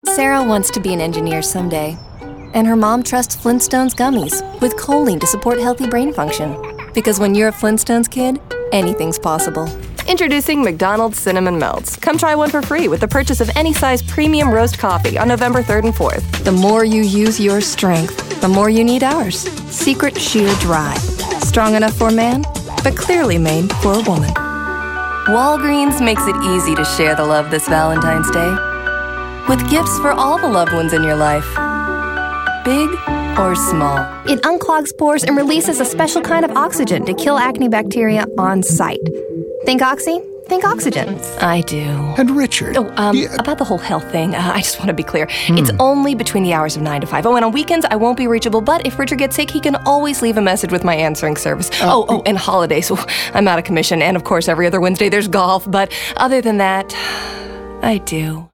commercial : women